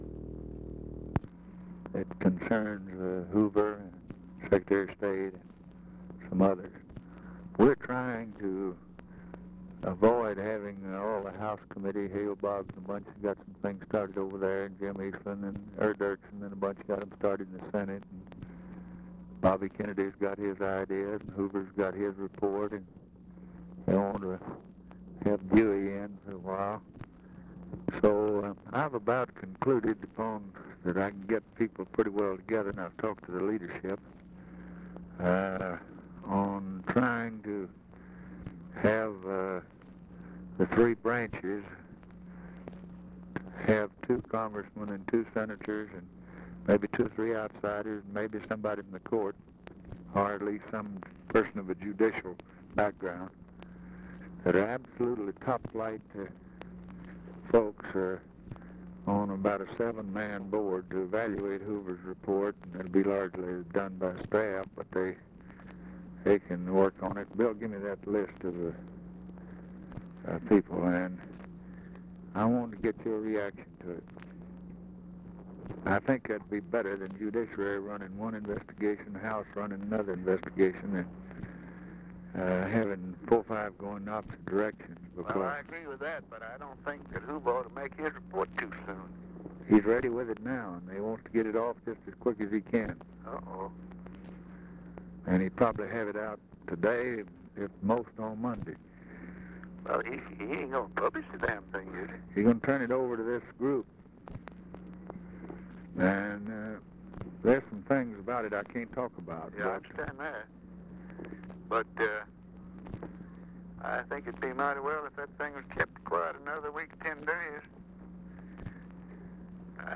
Conversation with RICHARD RUSSELL, November 29, 1963
Secret White House Tapes